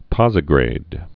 (pŏzĭ-grād)